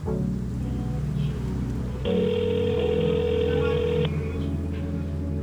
Нужен такой бас/пэд